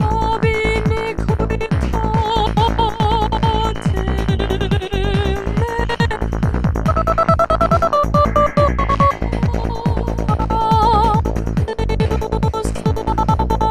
Gated Vocal effects are a cool way of adding extra rhythmic variations and dynamics to you tunes.
I’ve also highpassed the vocal to make it better sit in the mix.